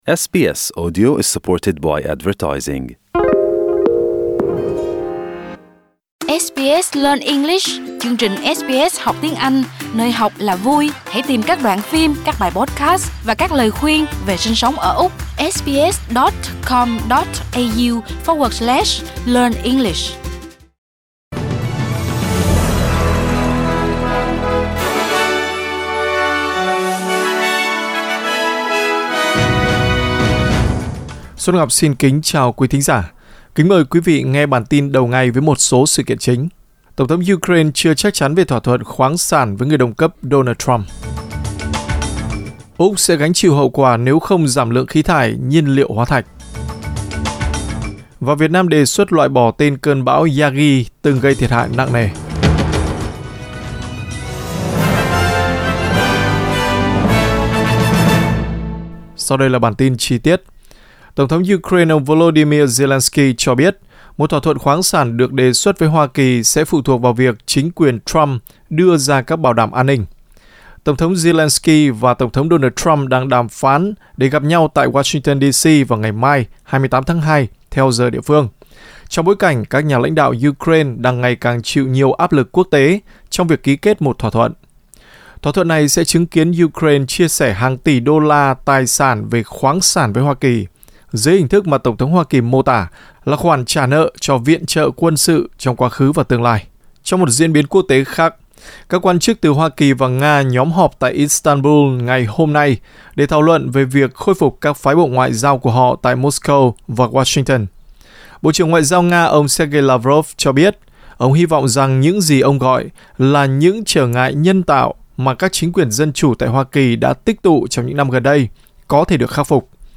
Bản tin nhanh đầu ngày có các nội dung chính.